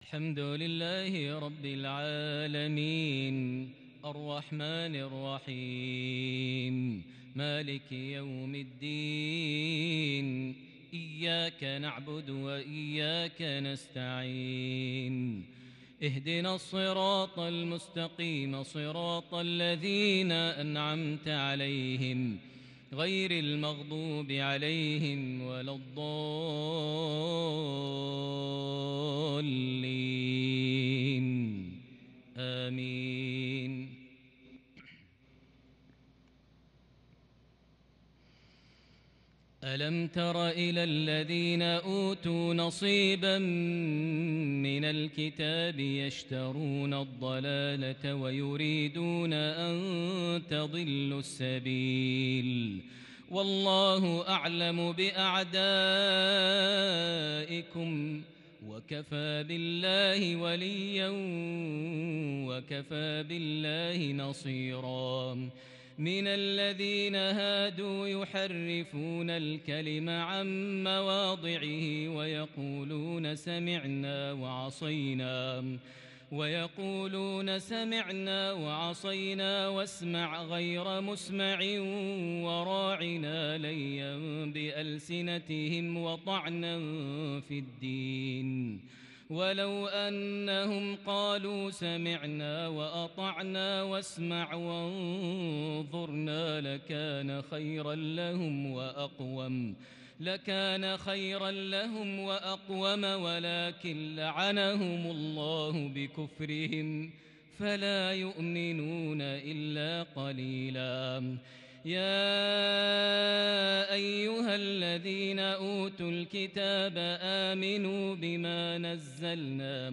تلاوة بأداء مبهر للغاية من سورة النساء (44-57) | عشاء 21 صفر 1442هـ > 1442 هـ > الفروض - تلاوات ماهر المعيقلي